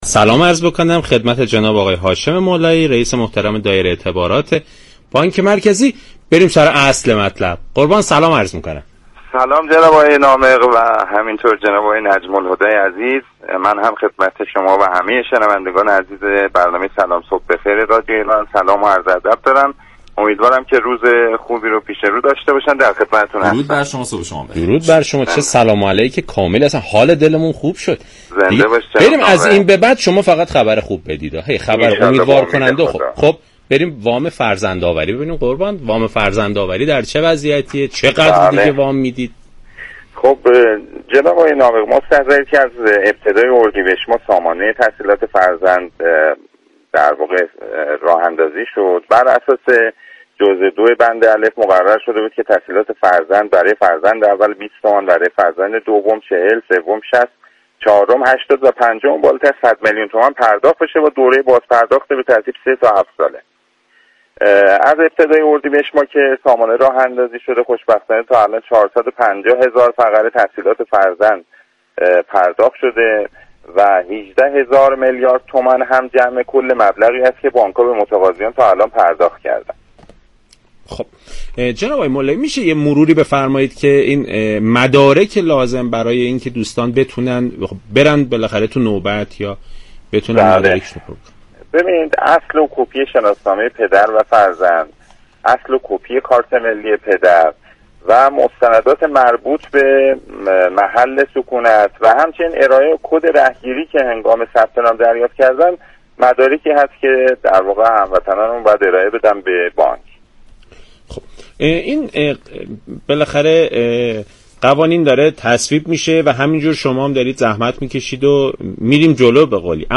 برنامه «سلام صبح بخیر» شنبه تا پنج شنبه هر هفته ساعت 6:35 از رادیو ایران پخش می شود.